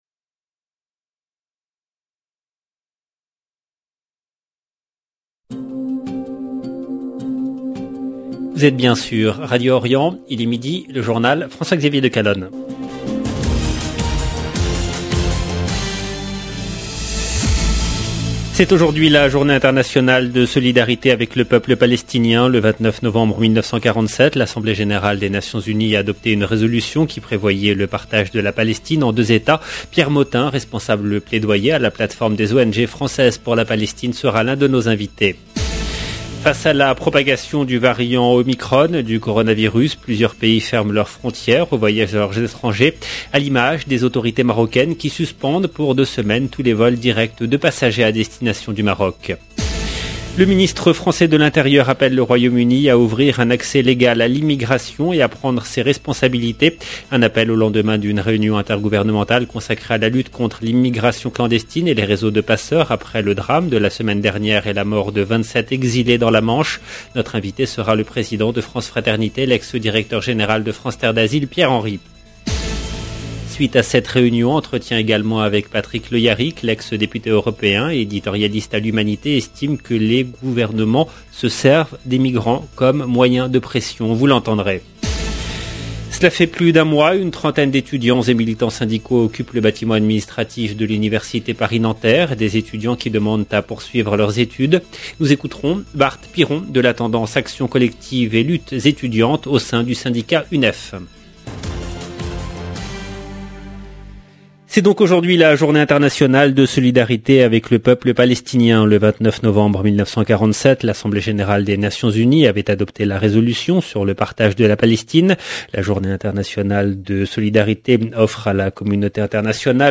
LE JOURNAL DE MIDI EN LANGUE FRANCAISE DU 29/11/21